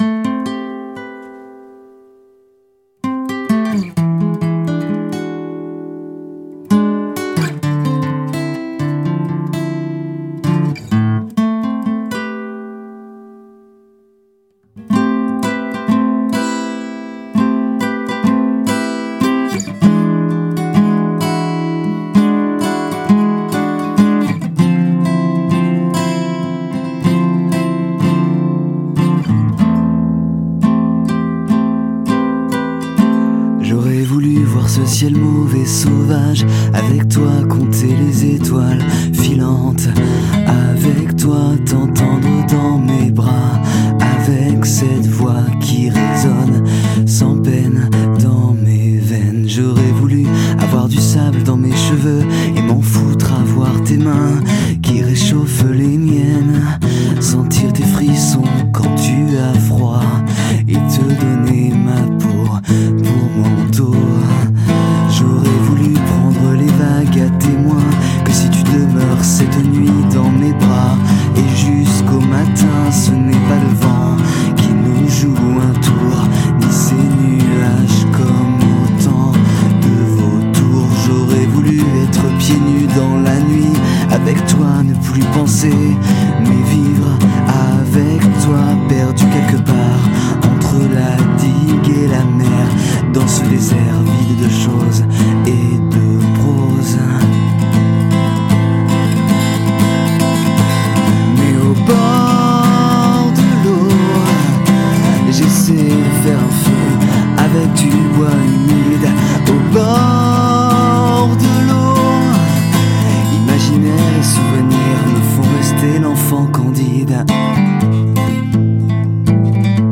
Le couple guitare/voix apporte un vrai charme à cet album.